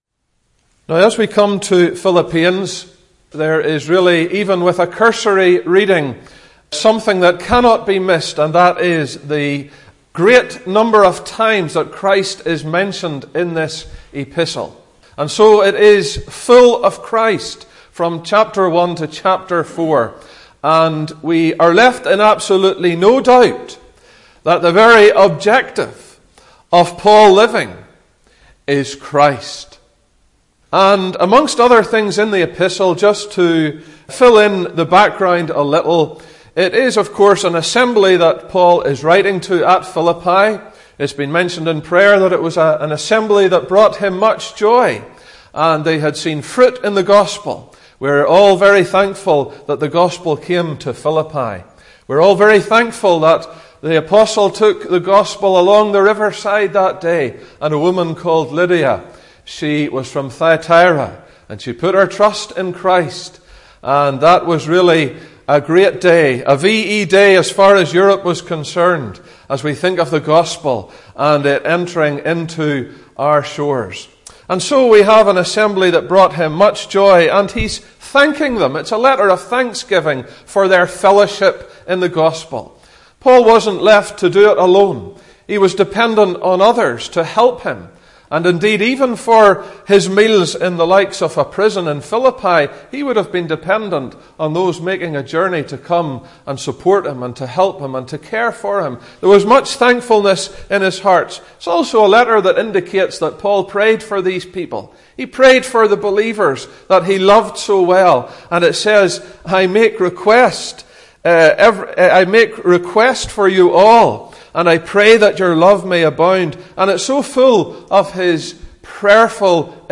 This message reorients our focus in the right direction as to the right motive for the Christian’s life and service (Message preached 9th Nov 2017)